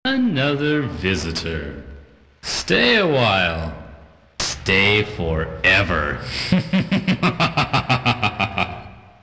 another visitor Impossible Mission 2 Commodore Amiga